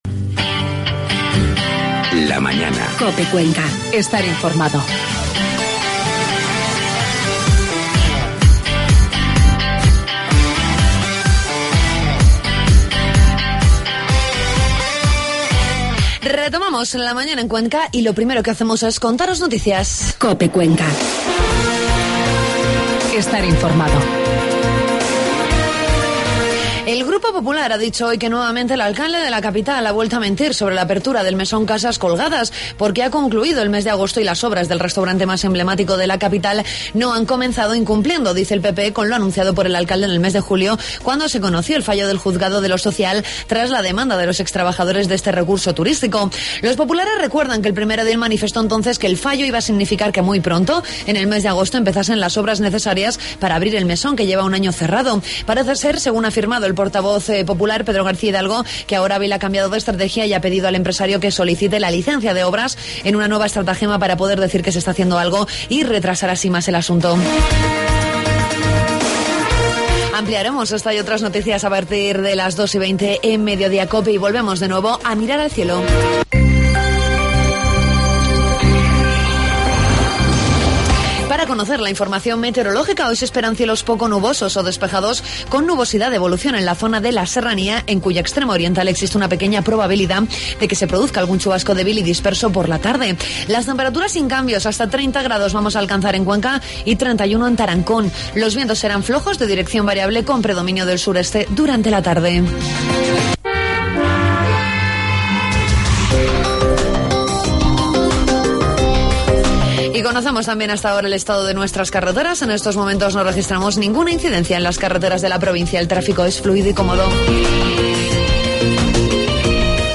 Hablamos también con uno de los ponentes del curso que el Centro de Excelencia va a llevar a cabo en El Provencio el día 17.